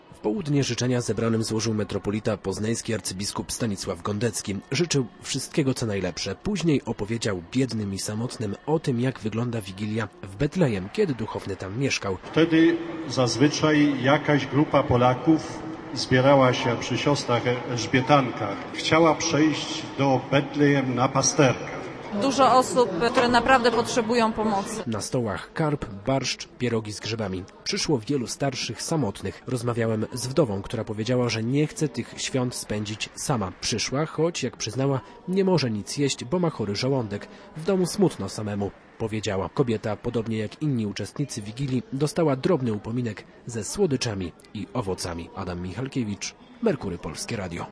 Ponad 1000 osób zasiadło do świątecznych stołów na poznańskich targach, gdzie archidiecezjalna Caritas zorganizowała spotkanie wigilijne. Uczestnicy Wigilii zjedli wspólny posiłek w wielkiej hali targowej.